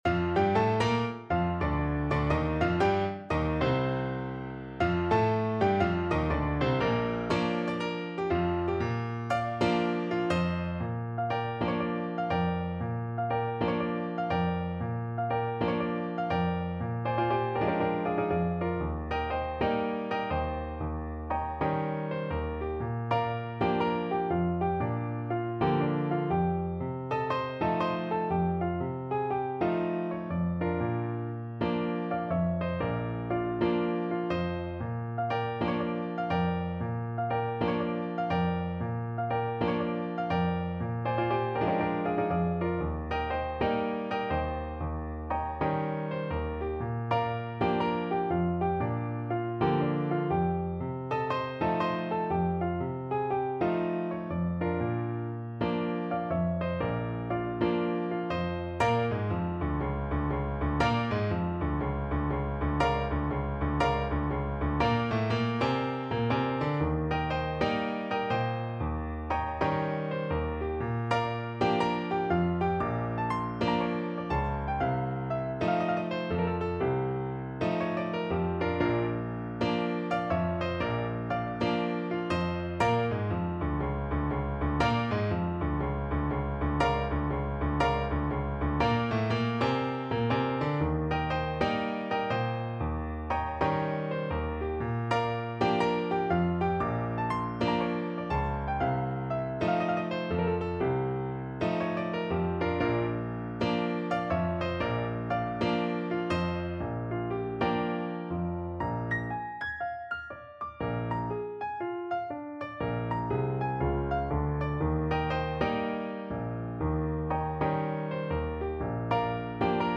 No parts available for this pieces as it is for solo piano.
4/4 (View more 4/4 Music)
Moderato = 120
Piano  (View more Advanced Piano Music)
Jazz (View more Jazz Piano Music)